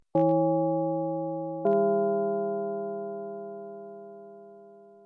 bell_short.mp3